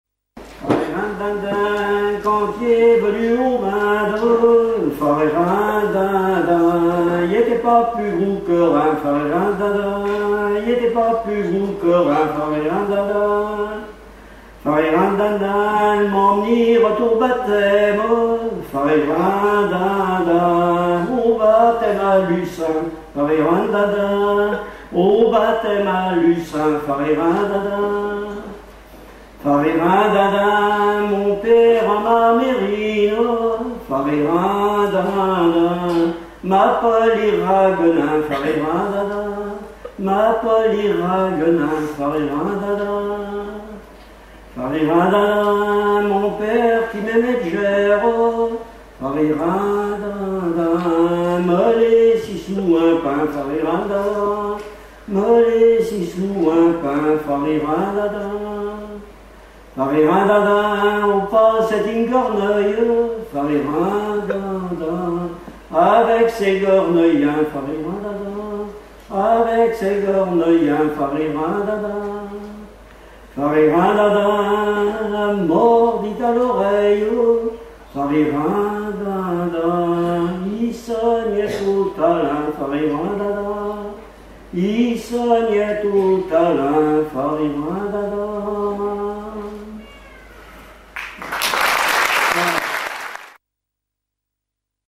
Langue Patois local
Genre laisse
Festival de la chanson pour Neptune F.M.
Pièce musicale inédite